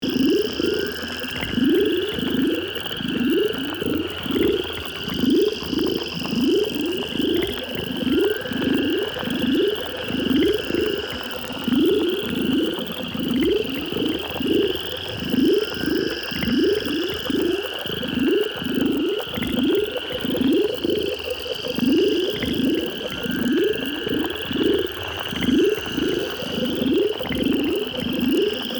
Download Free Sci-Fi Ambience Sound Effects | Gfx Sounds
This Sci-fi ambience sound effects collection features a wide range of immersive atmosphere sounds designed to bring your sci-fi environment to life.
Alien-laboratory-ambience.mp3